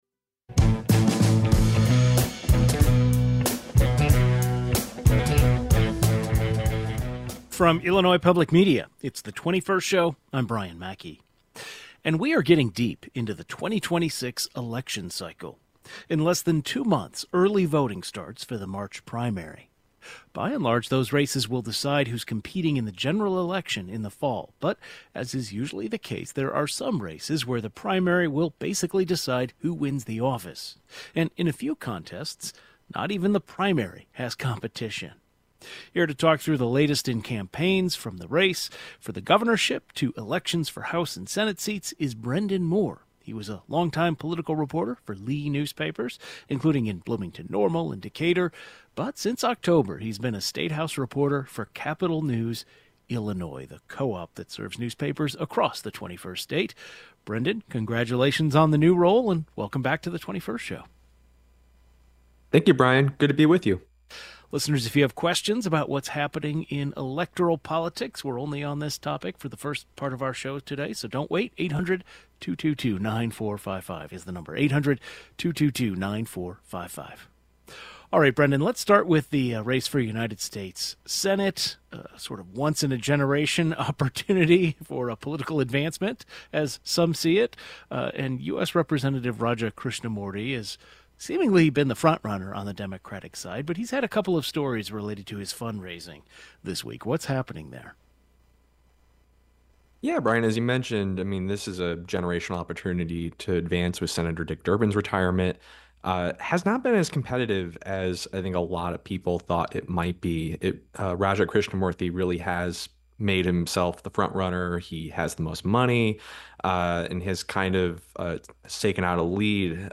A reporter covering Illinois politics talks through the latest in campaigns for the governor's race as well as House and Senate seats.